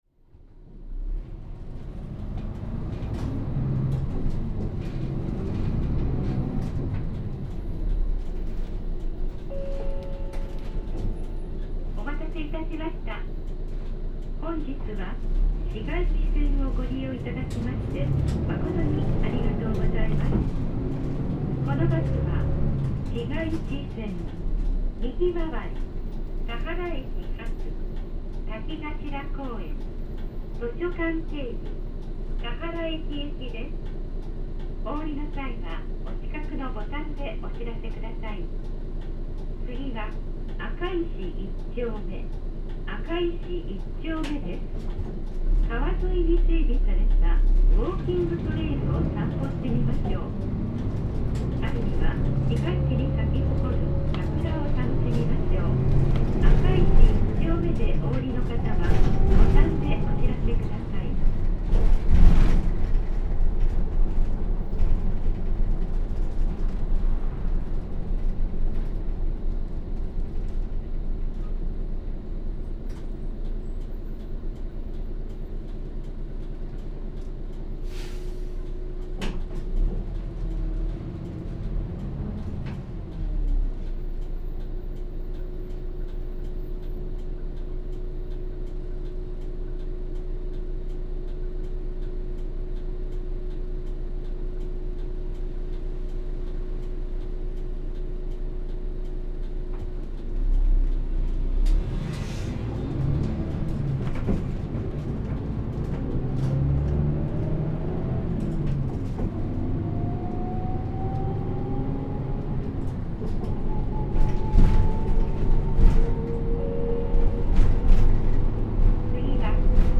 田原市ぐるりんバス 日野 KK-HR1JEEE ・ 走行音(全区間) (35.6MB★) 収録区間：市街地線右回り 田原駅前→田原駅前 日野レインボーHRの車体長7m車。
走行音は車体長さが短い分車体重量が軽くなるためか、9m車や10.5m車に比べ軽快な響きになっている。